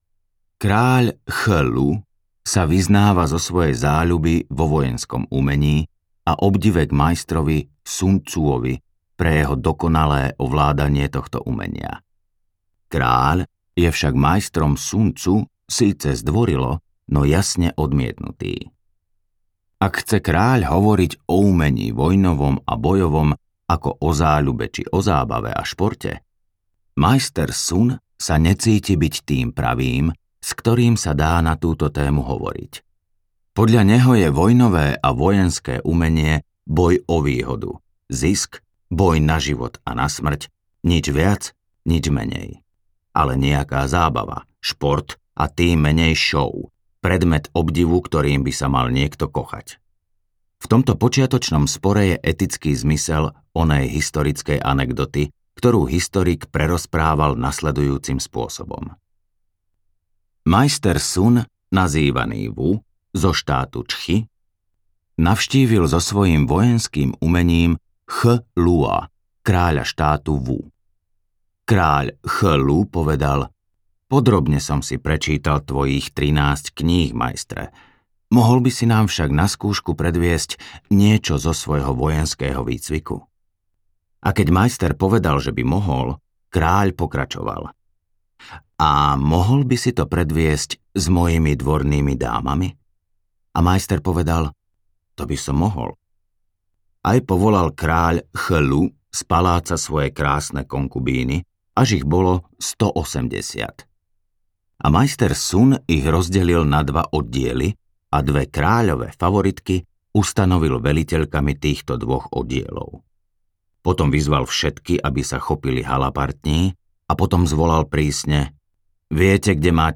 Umenie vojny audiokniha
Ukázka z knihy